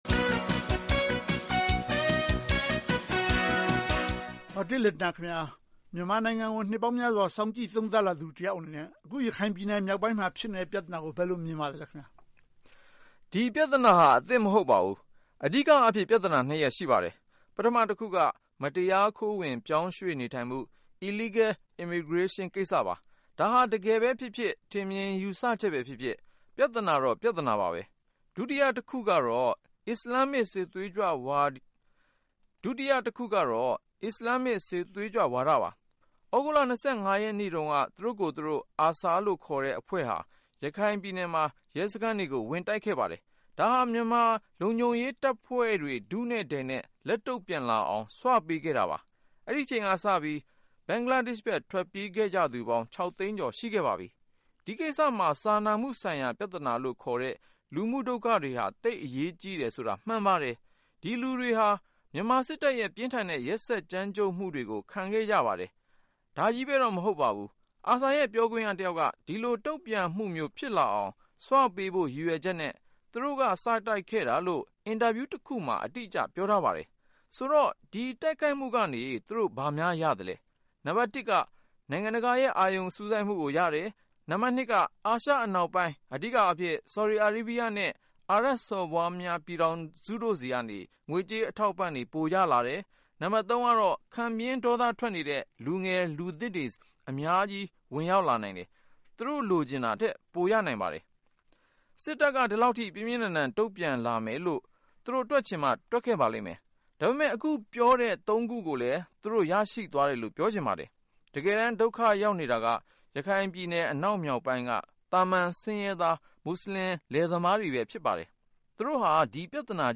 အင်တာဗျူးတခုမှာ